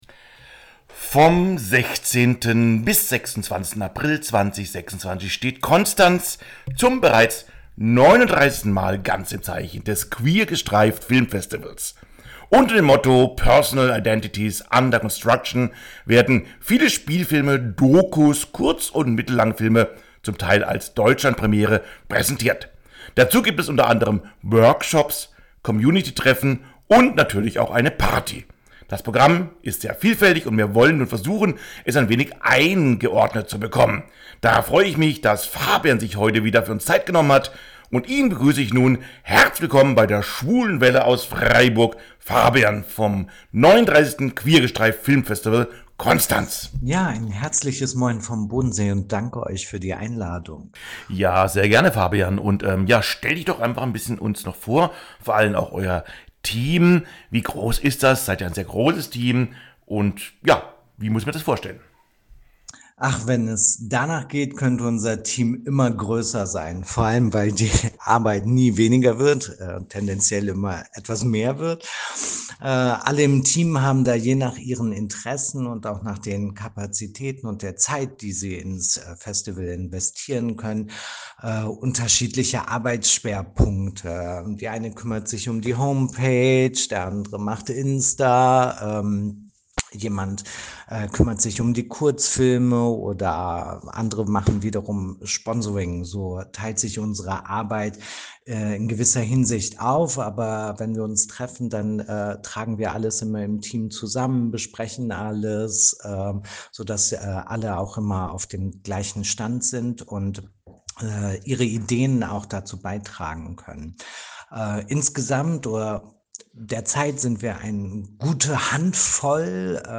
Es wurde ein sehr informatives Gespräch, dass Ihr nun als Podcast nachhören könnt.